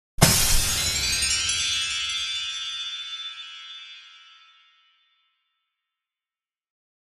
Звуки пуф
Звук магического взрыва